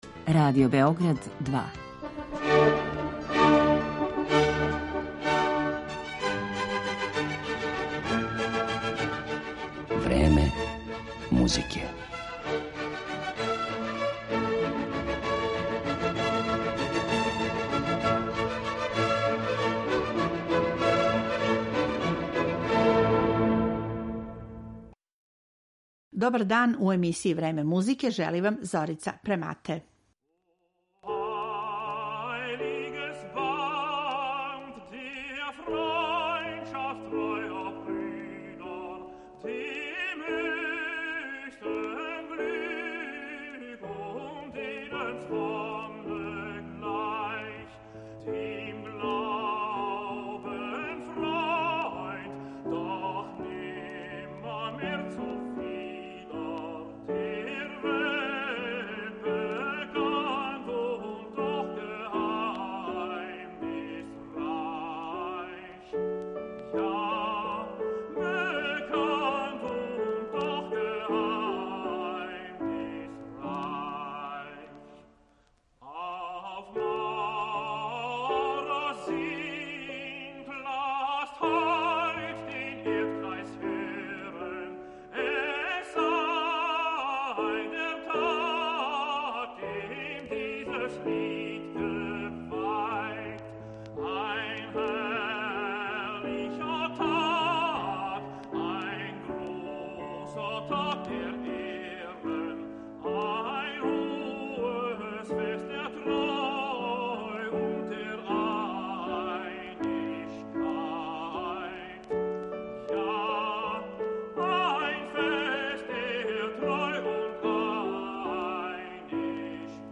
Почев од 1785. године компоновао је углавном песме уз камерни ансамбл или кантате са темама и стиховима који су одговарали масонским обредима, па ћемо емитовати управо избор из тог сегмента Моцартовог опуса.